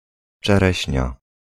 Ääntäminen
France (Paris): IPA: [yn sə.ʁiz]